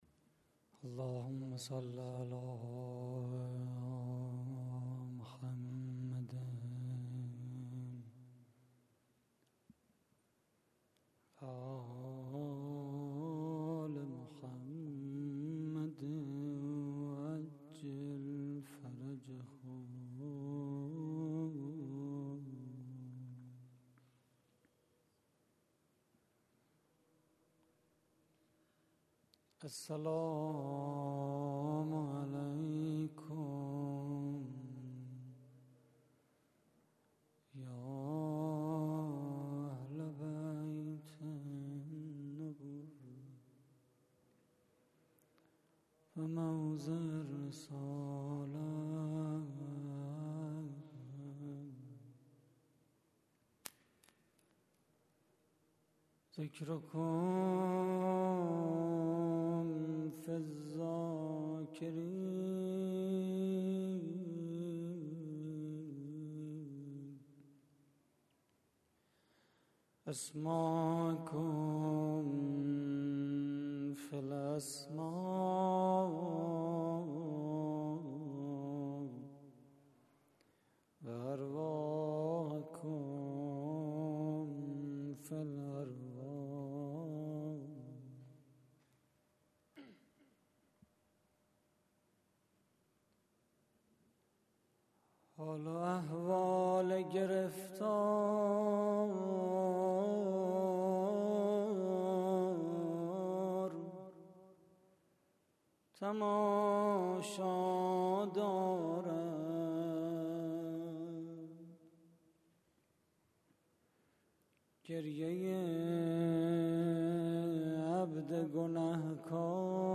مراسم عزاداری وفات حضرت زینب (س) / هیئت الزهرا (س) - دانشگاه شریف؛ 4 خرداد 92